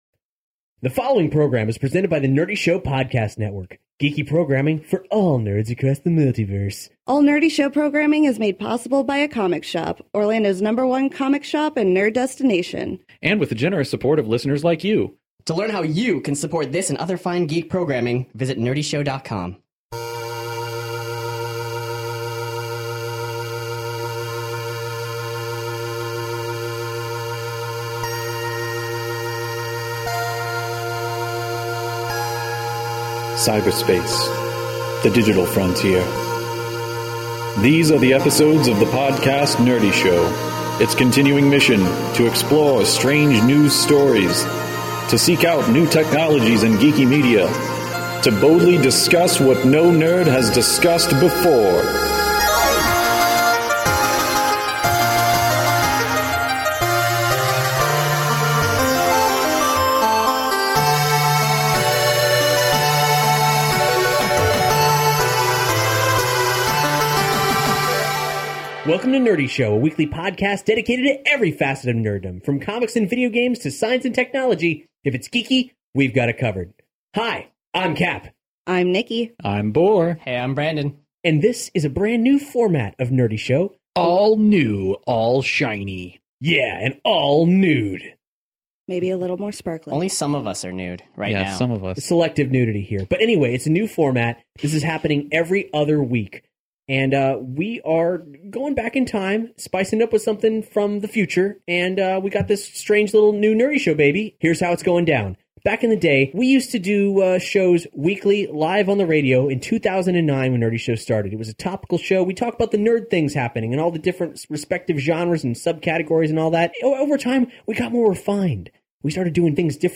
Announcing a new era of Nerdy Show - a back to basics approach where four nerds talk about the latest geek news across the genres.